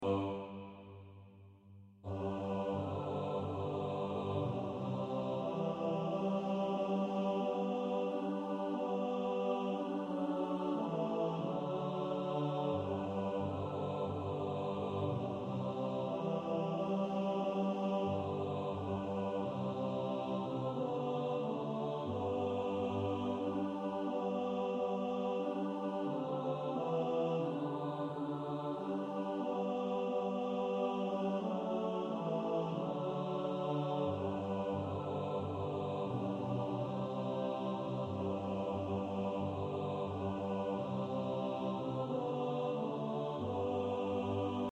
Bass Track.
Practice then with the Chord quietly in the background.